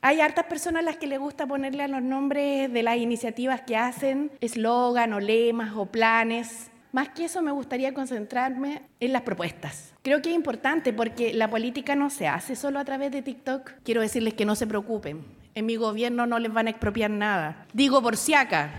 De esta manera, los aspirantes a La Moneda se reunieron en la región de Los Lagos, específicamente en el Teatro del Lago, en Frutillar, donde se está desarrollando el Salmón summit 2025.